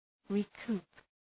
recoup.mp3